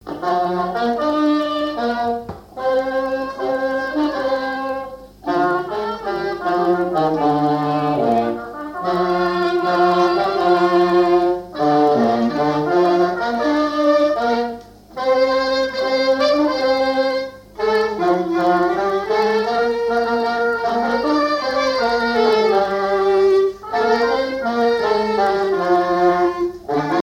trompette
saxophone
clarinette
circonstance : fiançaille, noce
Ensemble de marches de noces